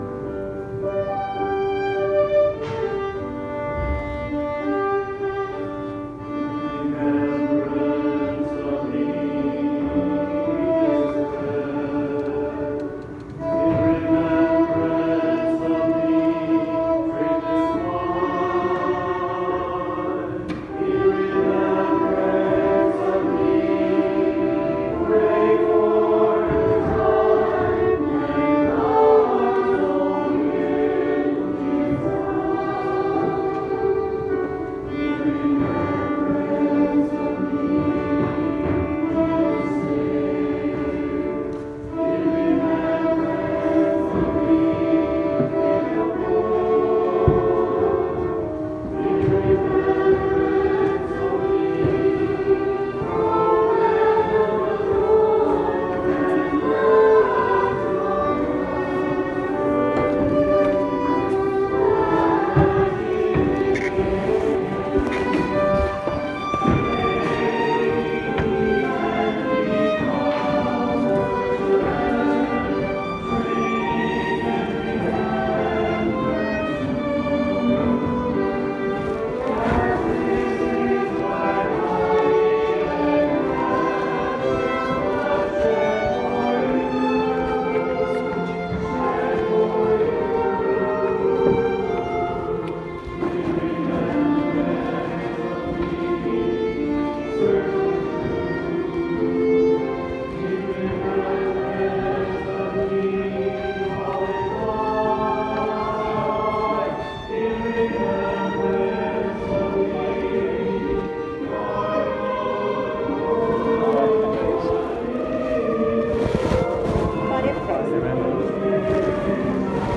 Holy Thursday